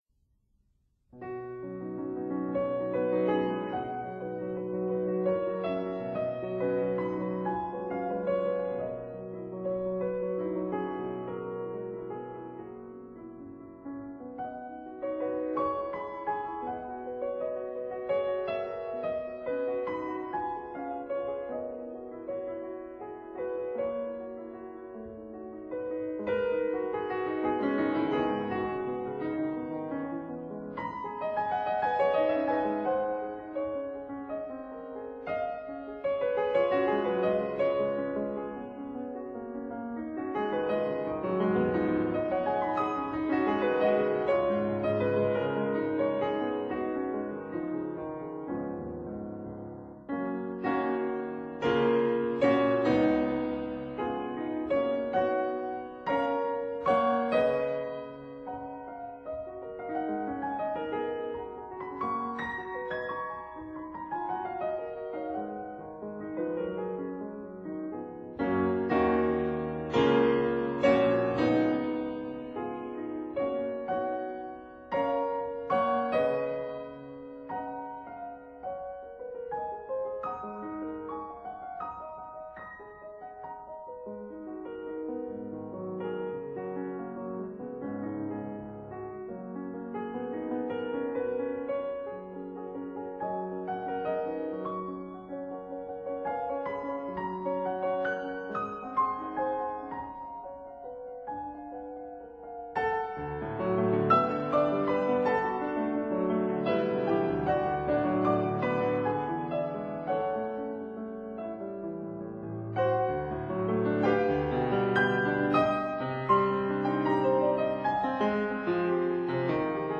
•(01) Piano Sonata No. 4 in C minor, Op. 27
piano